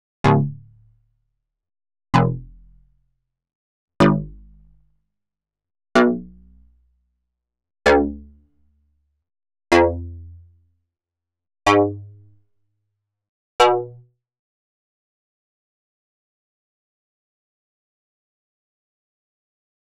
41_PPGShortBass_D+3_1-2.wav